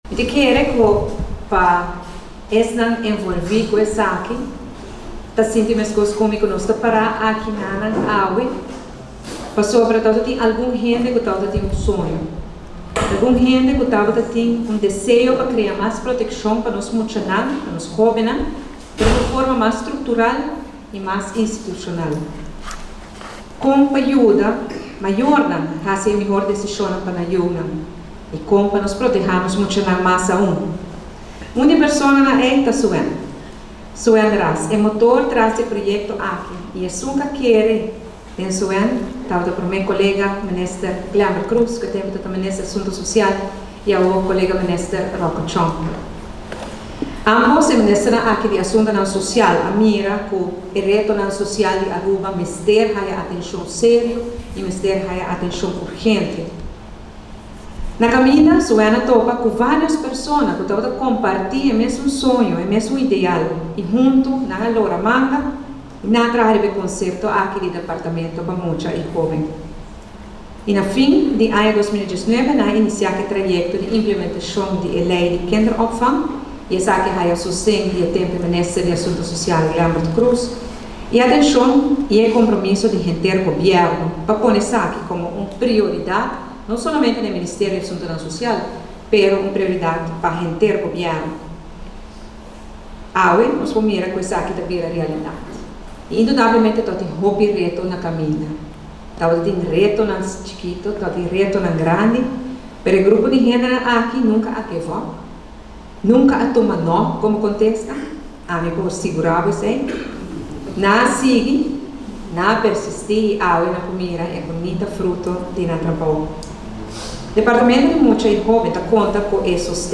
Departamento di mucha y hoben a keda lansa oficialmente , prome minister tabata presente y a hiba palabra unda cu pa prome biaha tin un departamento cu ta yuda guia e mayor con pa tuma miho decisionnan pa nan yiunan.